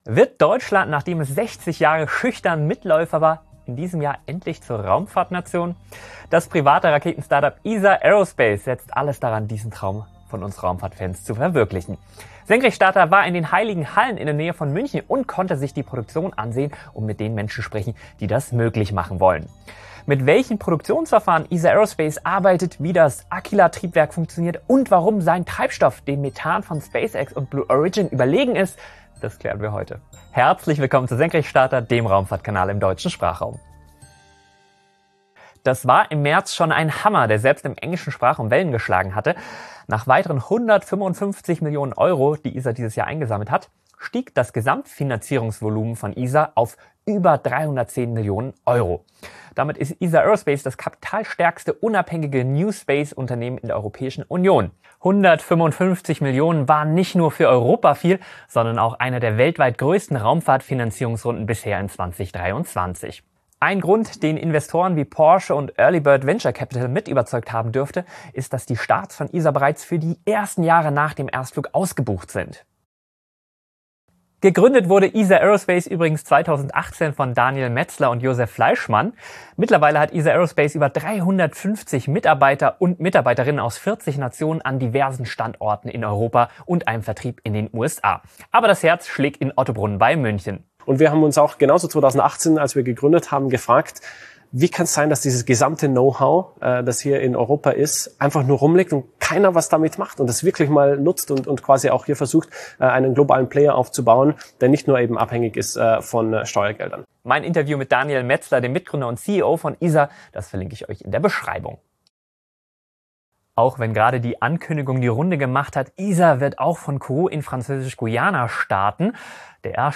Das private Raketen-Startup Isar Aerospace setzt alles daran, diesen Traum von uns Raumfahrt-Fans zu verwirklichen. SENKRECHTSTARTER war in den heiligen Hallen in der Nähe von München und konnte sich die Produktion ansehen und mit den Menschen sprechen, die das möglich machen wollen.